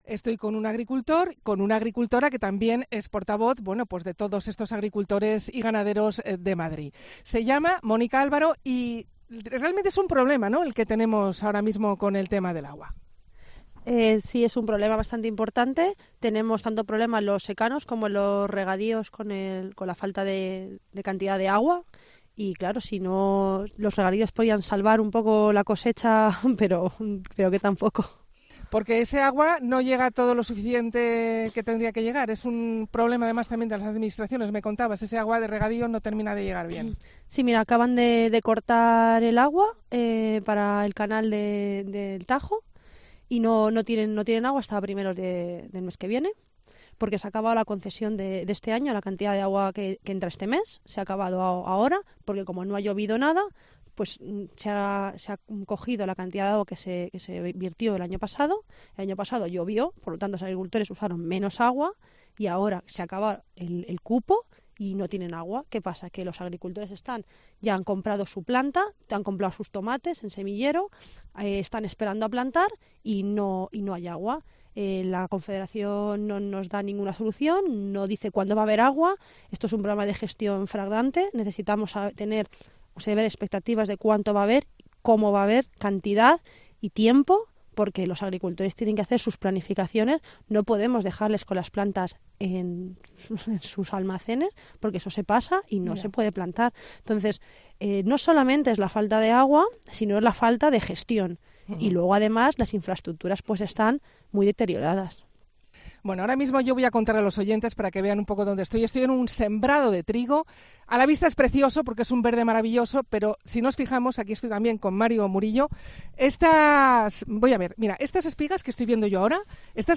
acude a un campo de cereal en Villar del Olmo para comprobar los estragos de la sequía
Cope en el campo madrileño